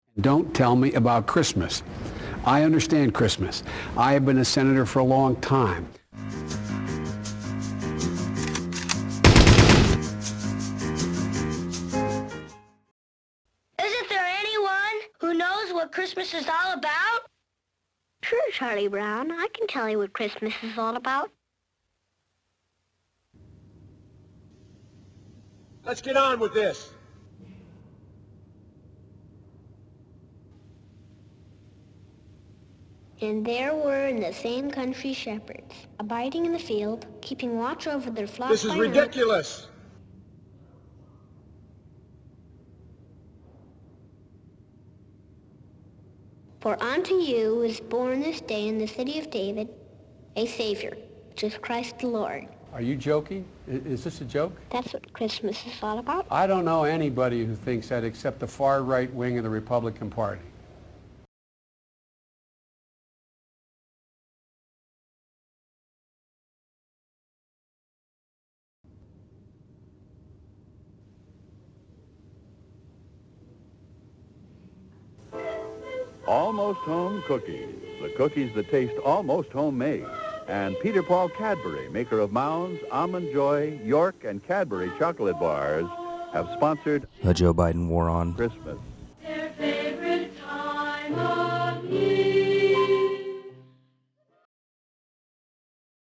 1 part beloved animated Christmas special
1 part Joe Biden YouTube clips.